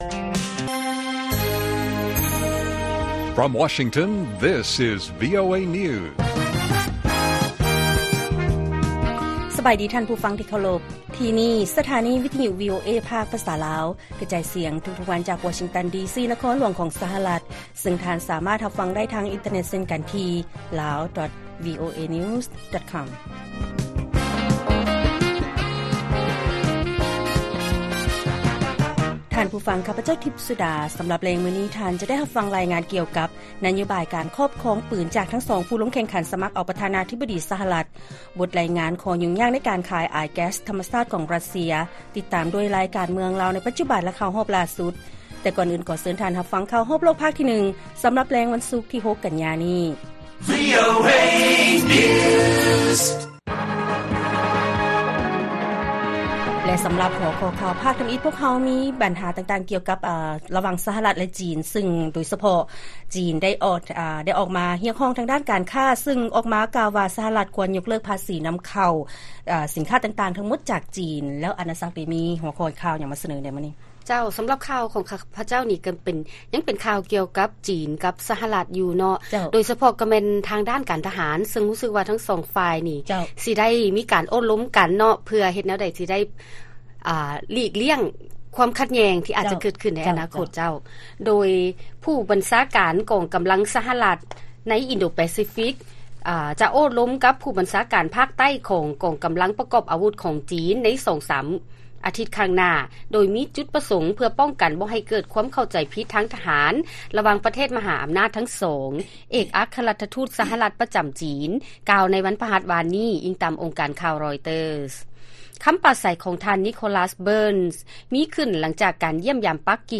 ວີໂອເອພາກພາສາລາວ ກະຈາຍສຽງທຸກໆວັນ, ຫົວຂໍ້ຂ່າວສໍາຄັນສໍາລັບແລງມື້ນີ້ ມີດັ່ງນີ້: 1. ຜູ້ບັນຊາການຂອງ ສຫລ ແລະ ຈີນ ຈະປຶກສາຫາລືກ່ຽວກັບການປ້ອງກັນຄວາມຂັດແຍ້ງ, 2. ຈີນ ຮຽກຮ້ອງໃຫ້ ສຫລ ຍົກເລີກພາສີທັງໝົດຕໍ່ສິນຄ້າຂອງຈີນໃນທັນທີ, ແລະ 3. ນັກລົບໂຣຮິງຢາ ແລະ ລັດຖະບານທະຫານ ປະເຊີນກັບສັດຕູກຸ່ມດຽວກັນ ຢູ່ໃນສະໜາມລົບ.